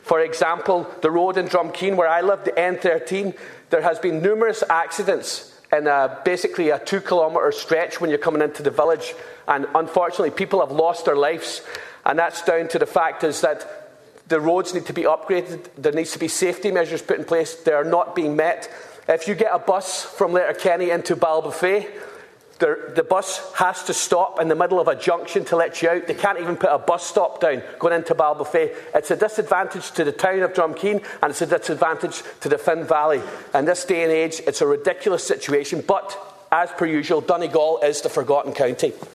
That was the blunt message from Donegal Deputy Charles Ward as he spoke in the Dáil during a motion on the rising costs of motoring.